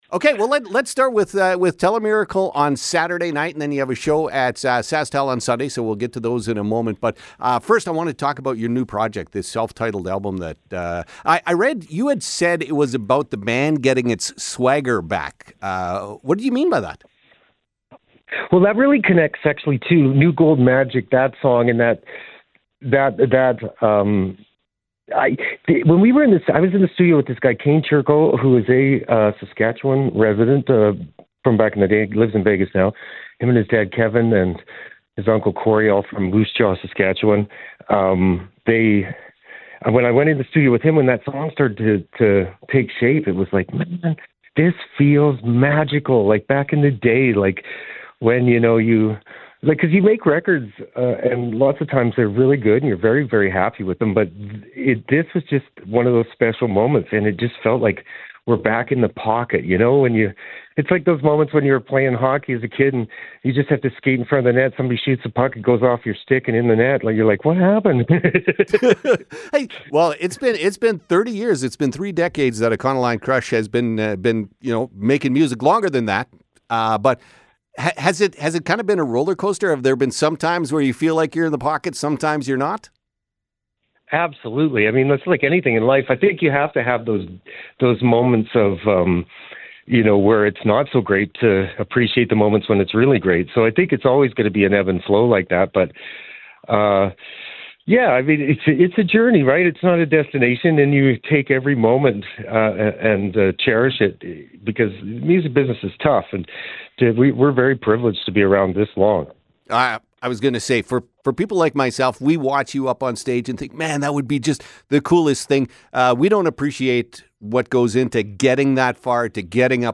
Trevor Hurst from Econoline Crush joins the show